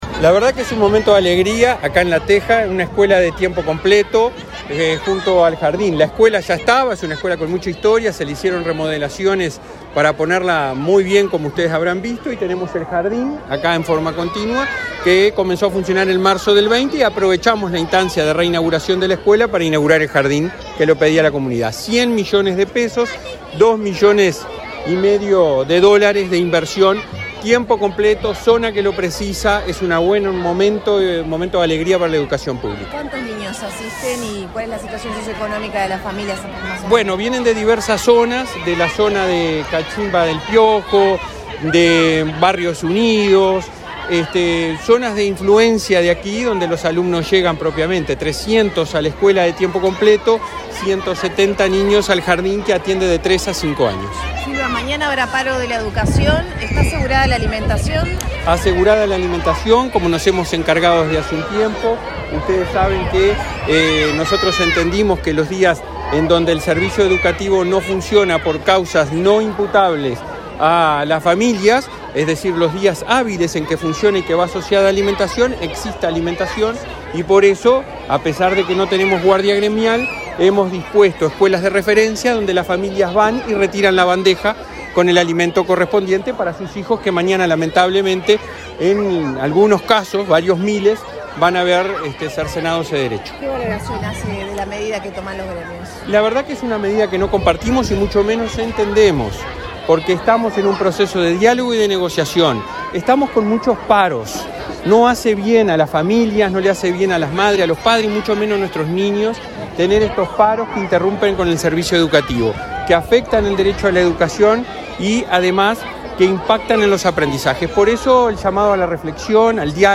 Declaraciones del presidente de ANEP, Robert Silva
Declaraciones del presidente de ANEP, Robert Silva 20/06/2023 Compartir Facebook X Copiar enlace WhatsApp LinkedIn Este martes 20, la Administración Nacional de Educación Pública (ANEP) presentó, en el barrio de La Teja, en Montevideo, la ampliación de la escuela n.º 9 e inauguró el jardín de infantes n.º 388, ubicado en el mismo predio. El presidente de la ANEP, Robert Silva, dialogó con la prensa antes del acto.